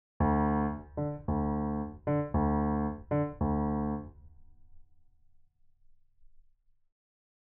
Step 1: bass line
Start with this basic pattern in your left hand:
How-Insensative-bassline.mp3